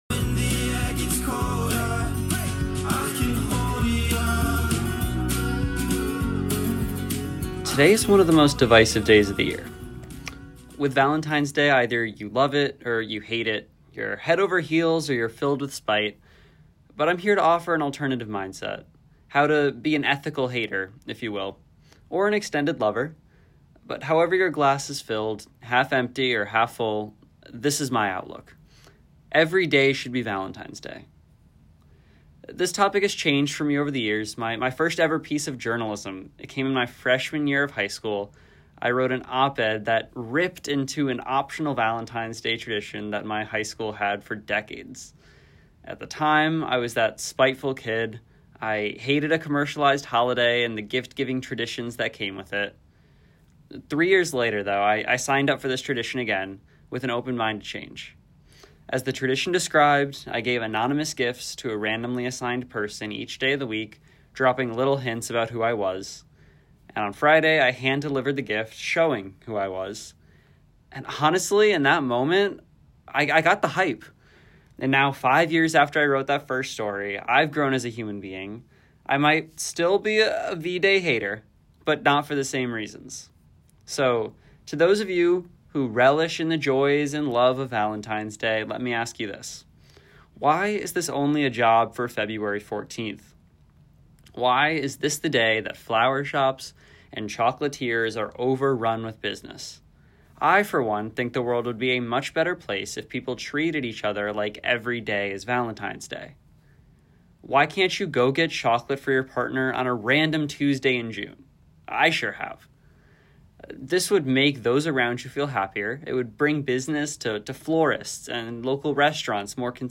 Every Day Should Be Valentine’s Day: An Audio Op-Ed